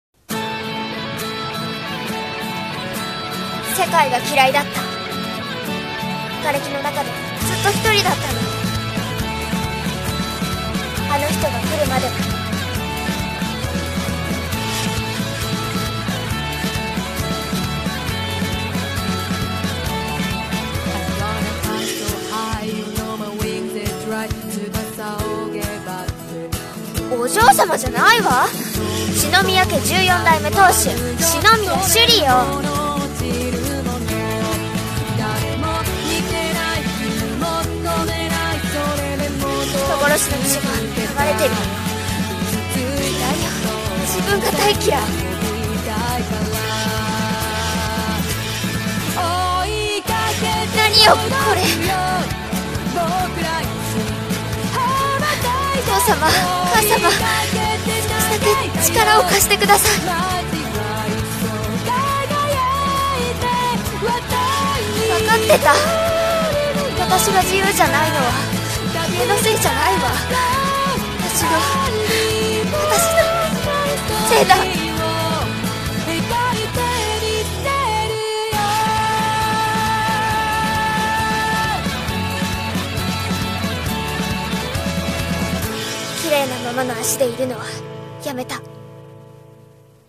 【アニメ告知CM風声劇台本】CLONE～朱風篇～【２人声劇】